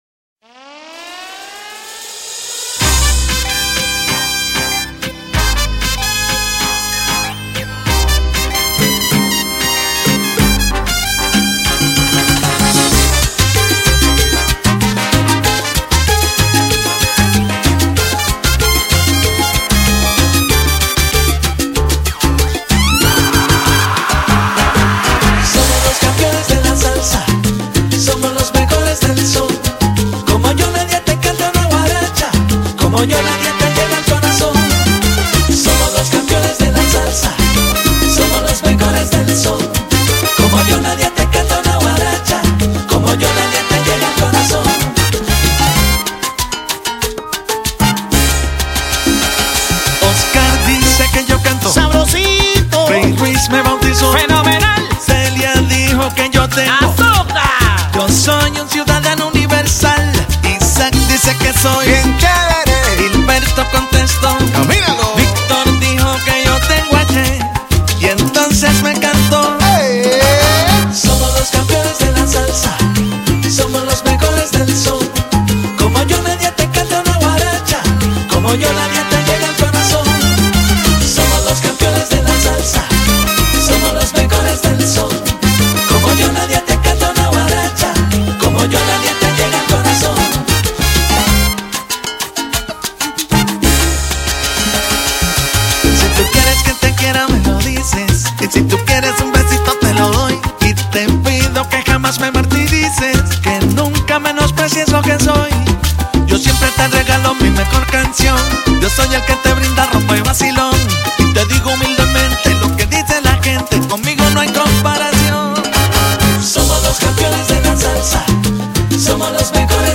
А ещё там духовые классные.